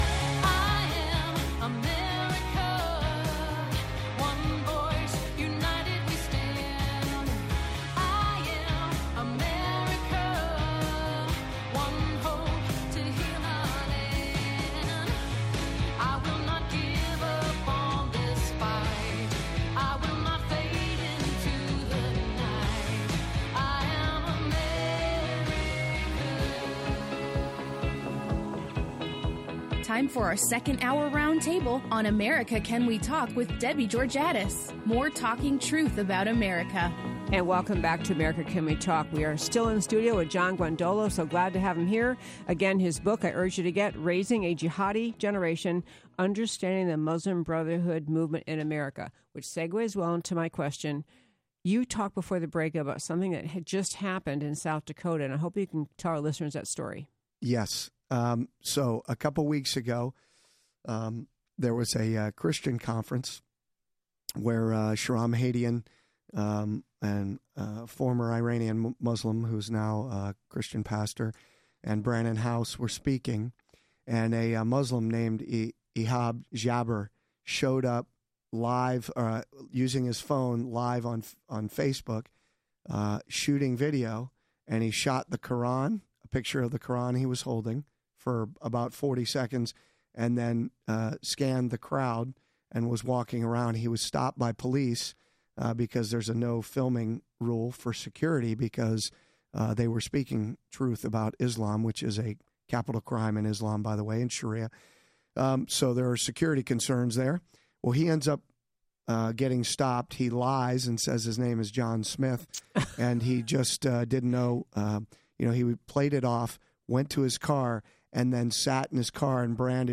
Hr2: Dan Patrick Interview: College Free Speech
23 Apr Hr2: Dan Patrick Interview: College Free Speech Posted at 19:14h in Radio Show Podcasts by admin Listen to the second hour of the April 23rd show, here .